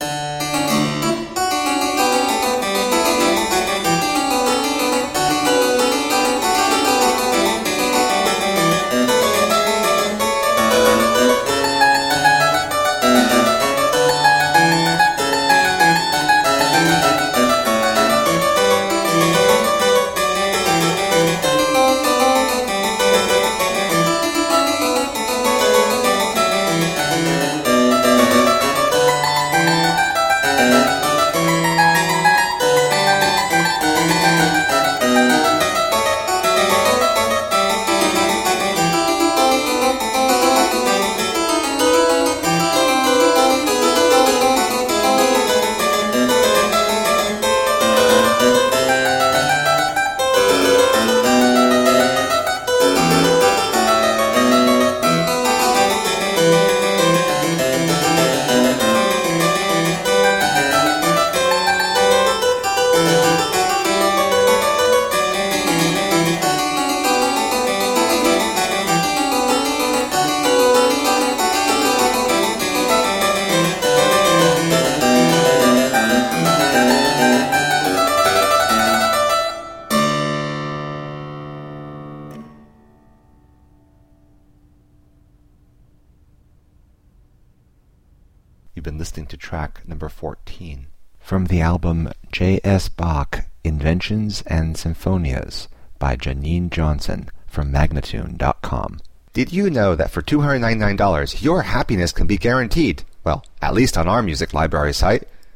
Classical, Baroque, Instrumental
Harpsichord